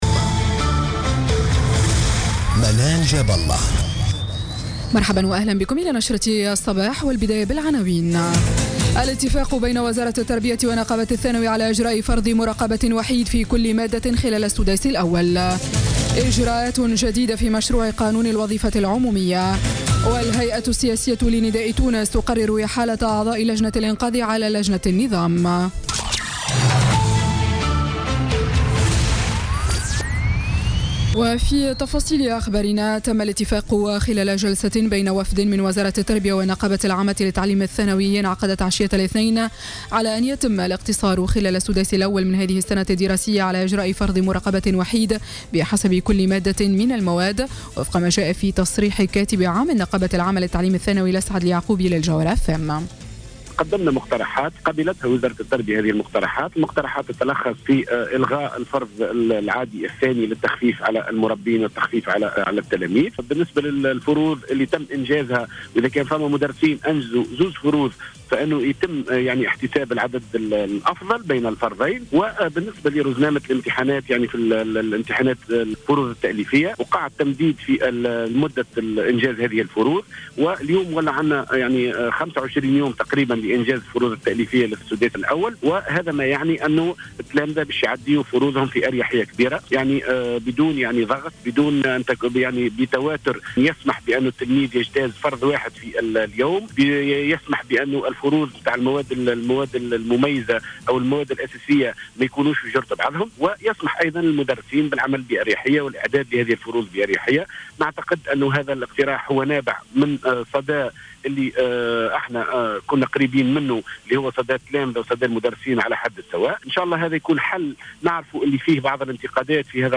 نشرة أخبار السابعة صباحا ليوم الثلاثاء 22 نوفمبر 2016